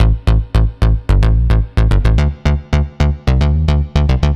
AM_OB-Bass_110-A.wav